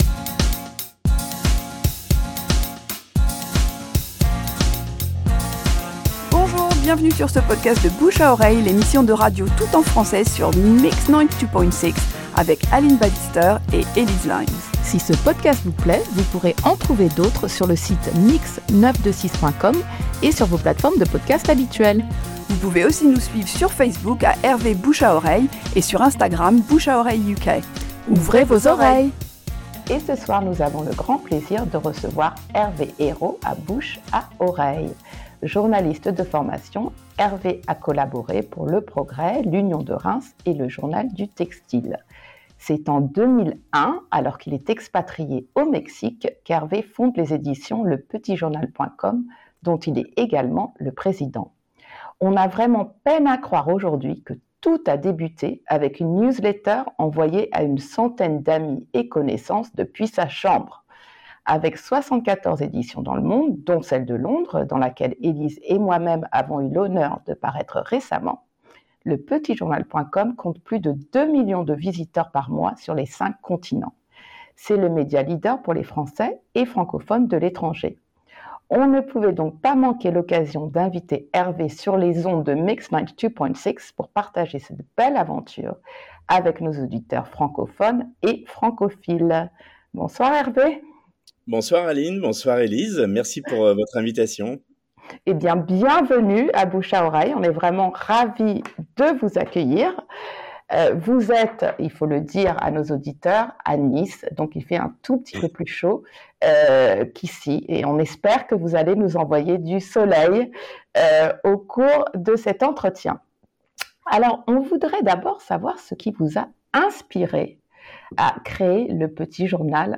Bouche à Oreille: En conversation